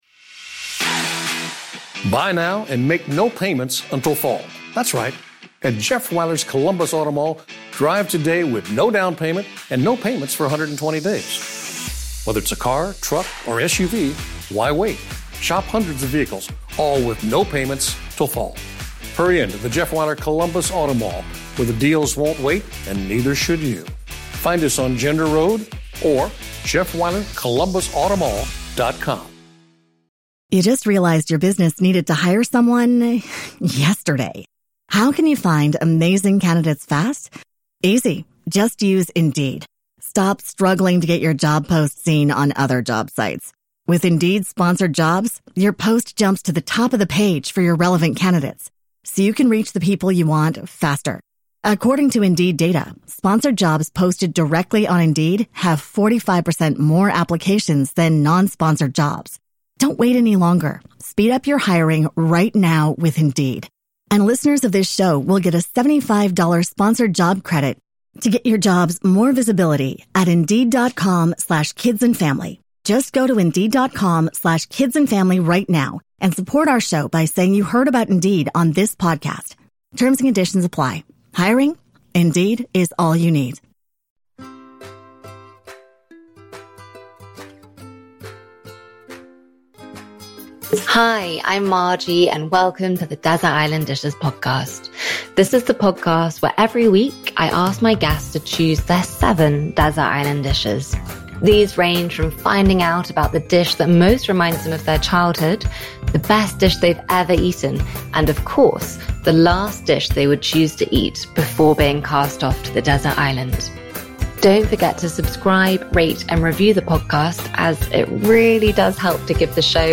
My guest today is Thomasina Miers